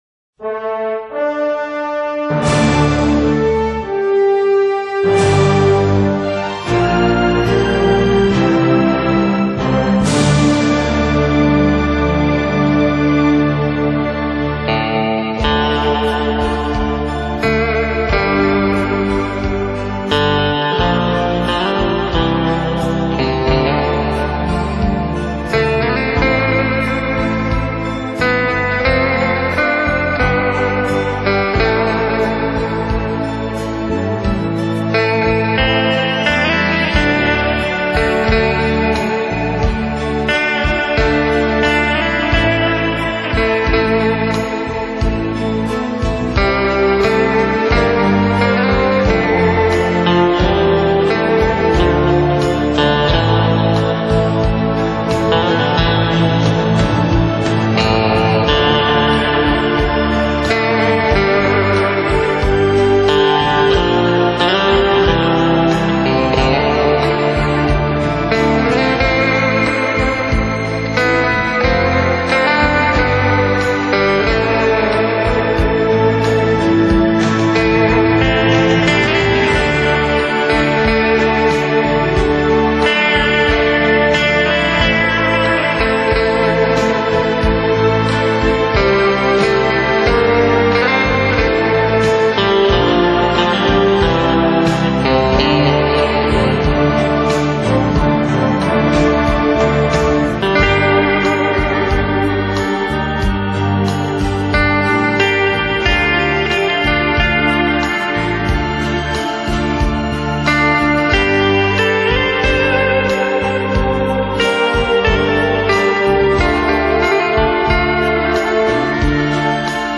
悠扬的电吉他